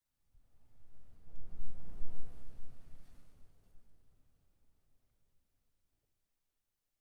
minecraft / sounds / block / sand / wind1.ogg
wind1.ogg